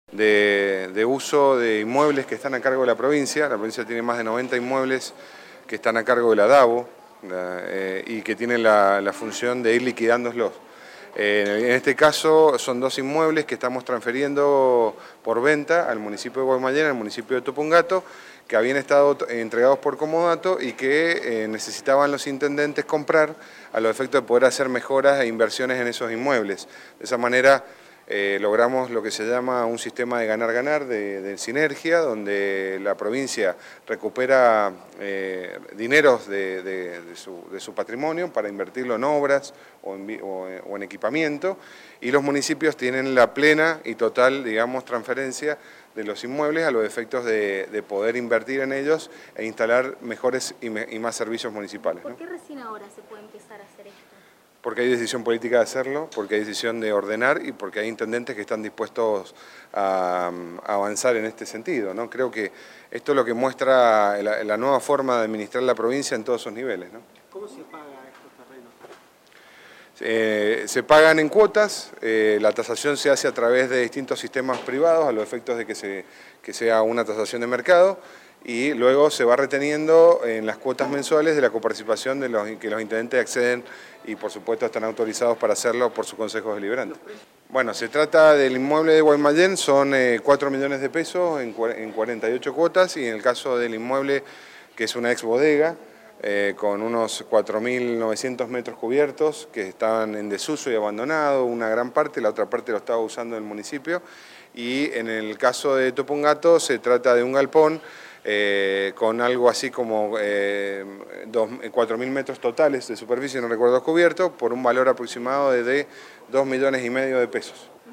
VENTA-DE-INMUEBLES-DE-DAABO-MARTIN-KERCHNER-MIN-HACIENDA.mp3